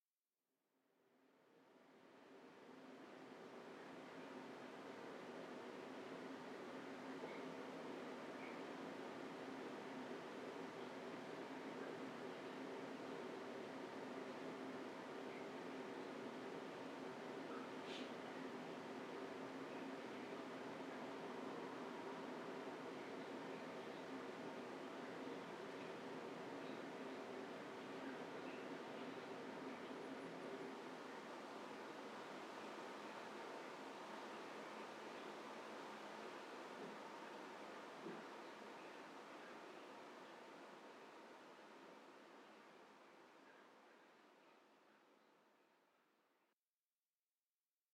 04_书店内.ogg